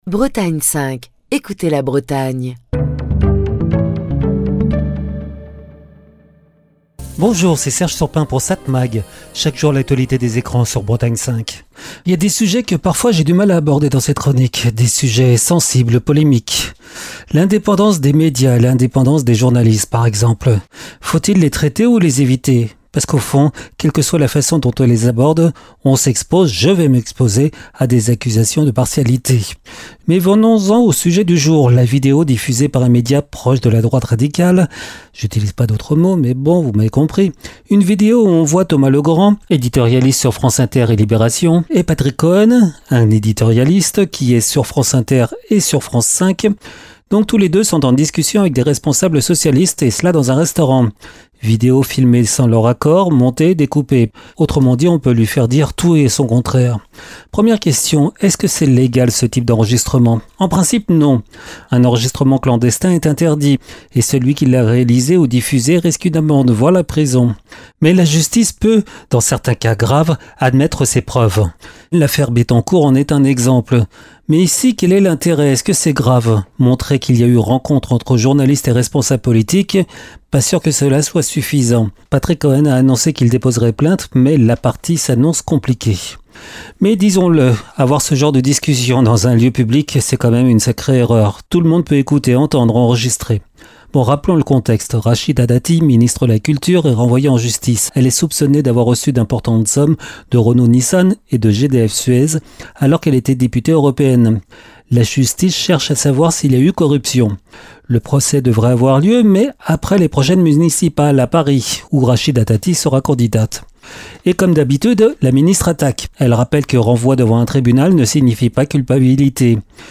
Chronique du 15 septembre 2025.